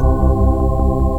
ORGAN-05.wav